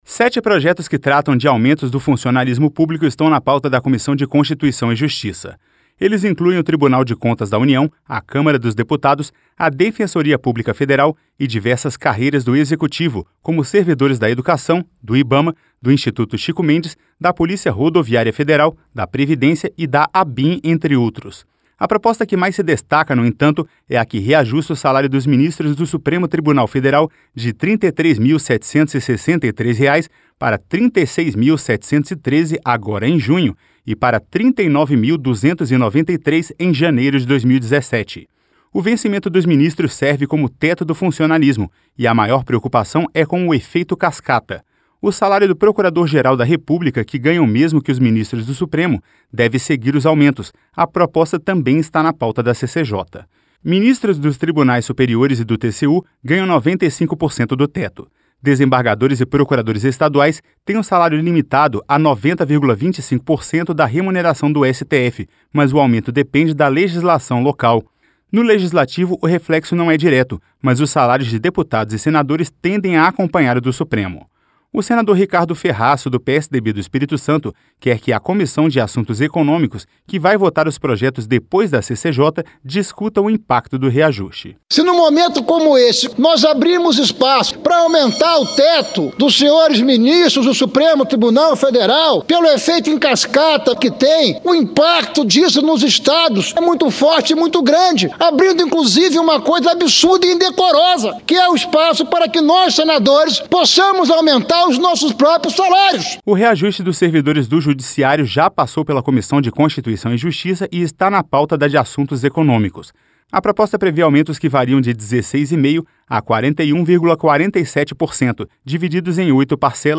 (Repórter) Sete projetos que tratam de aumentos do funcionalismo público estão na pauta da Comissão de Constituição e Justiça.